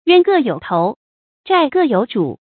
注音：ㄧㄨㄢ ㄍㄜˋ ㄧㄡˇ ㄊㄡˊ ，ㄓㄞˋ ㄍㄜˋ ㄧㄡˇ ㄓㄨˇ